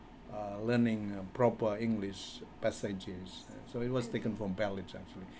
S1 = Indonesian male S2 = Malaysian female Context: S1 is talking about learning English when he was young.
It is pronounced as [ˈbælɪdʒ] . The open vowel in the first syllable, the stress on the first syllable, and the [ˈdʒ] at the end of the word all contribute to the problem.